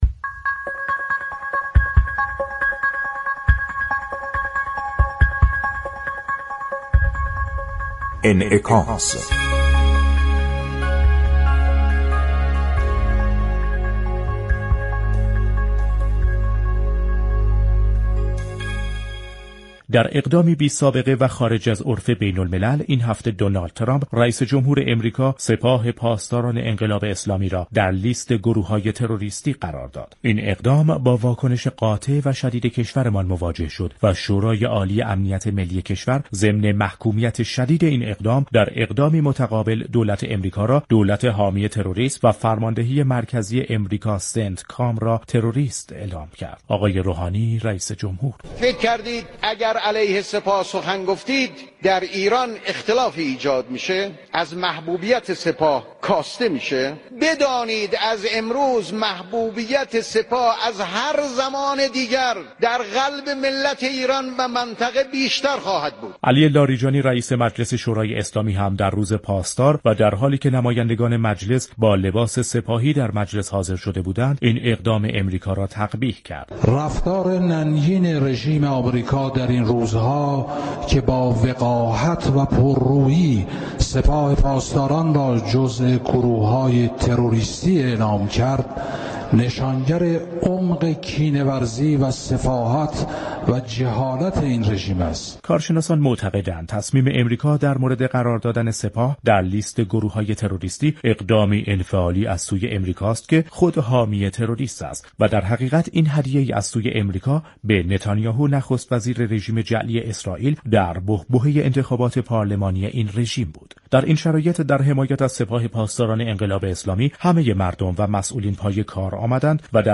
ابوالفضل ظهره وند از دیپلمات های باسابقه كشور در بخش انعكاس برنامه رویدادهای هفته رادیو ایران گفت : اقدام امریكا علیه سپاه در نوع خود بی سابقه است